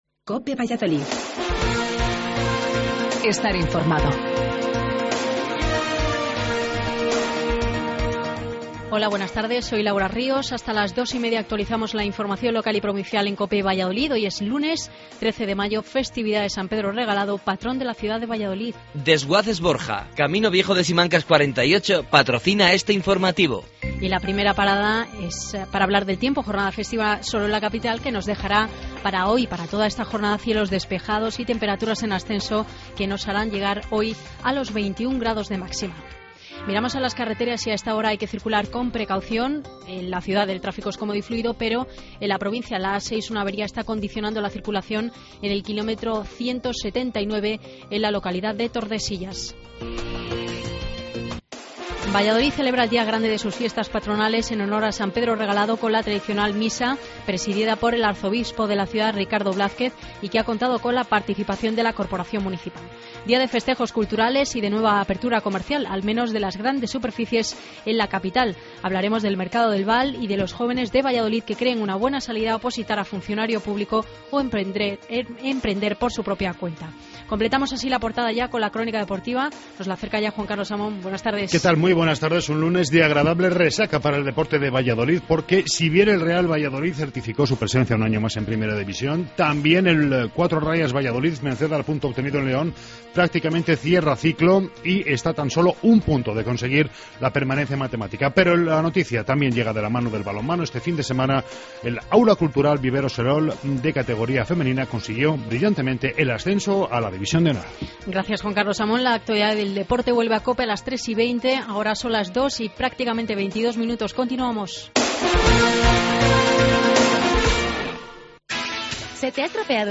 AUDIO: Informativo local